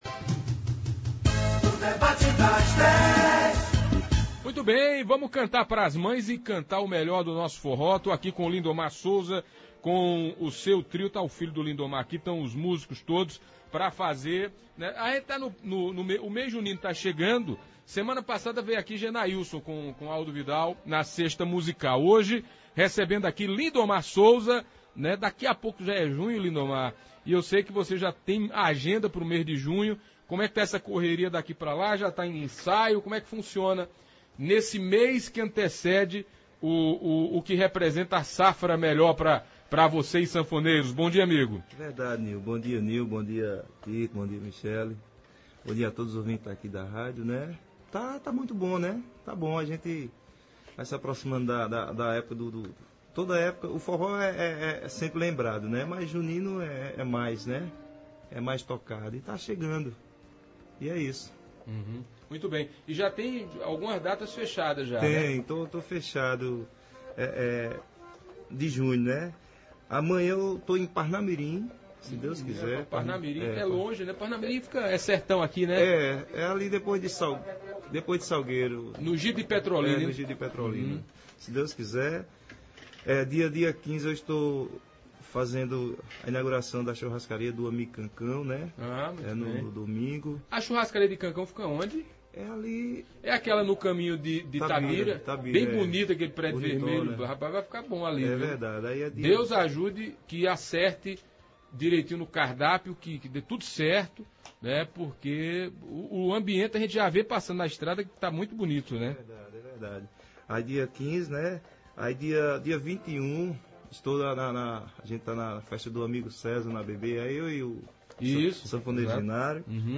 sanfoneiro